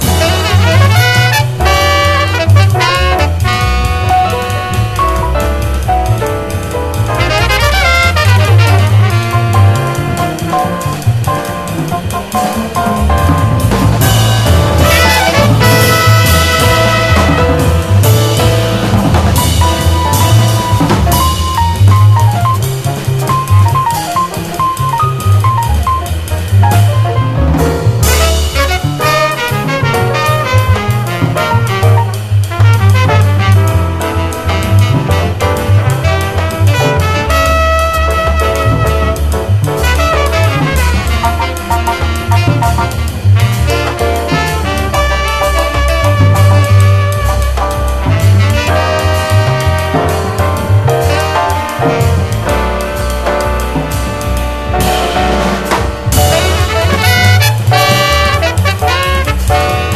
ROCK / 70'S / PROGRESSIVE ROCK